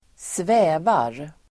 Uttal: [²sv'ä:var]